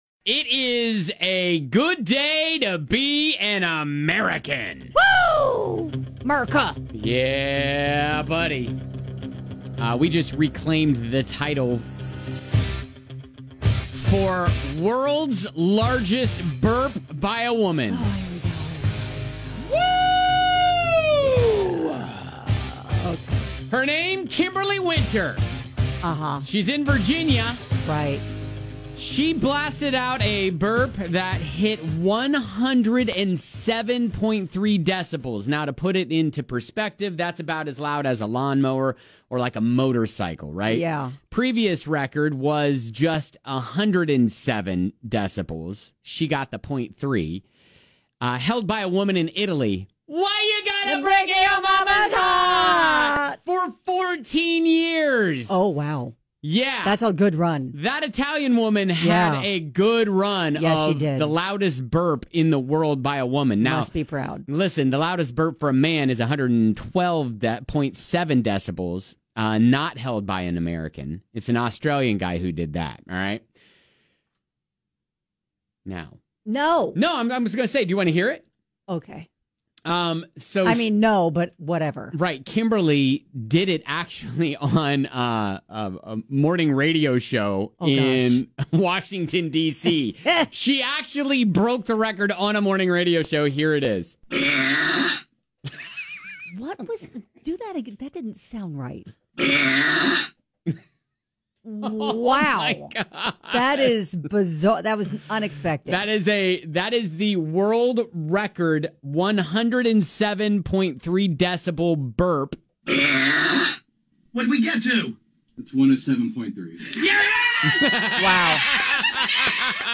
That's about as loud as a lawnmower or motorcycle.